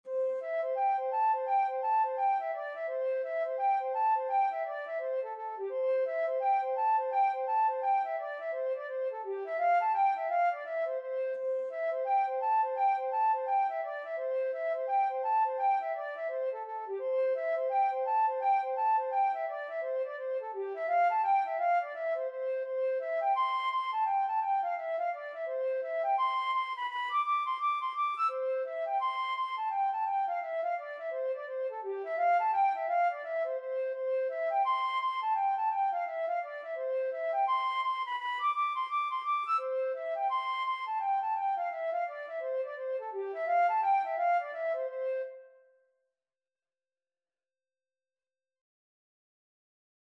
Flute version
4/4 (View more 4/4 Music)
G5-E7